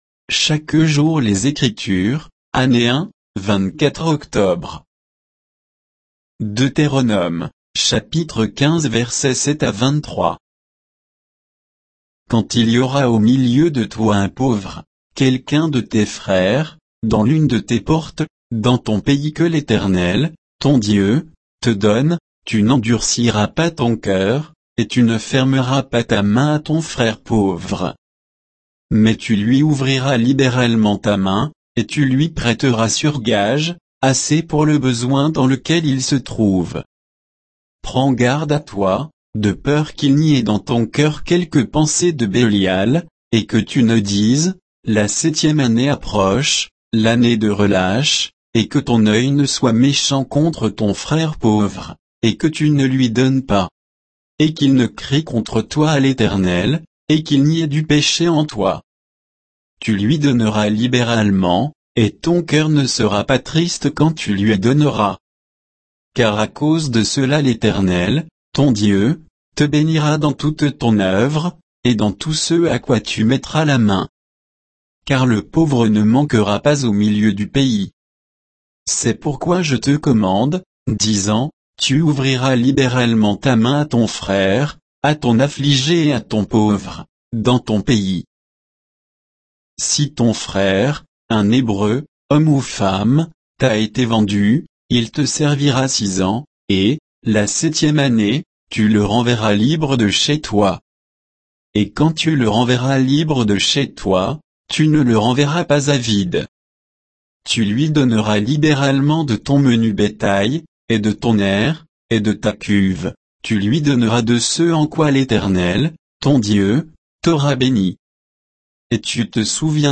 Méditation quoditienne de Chaque jour les Écritures sur Deutéronome 15, 7 à 23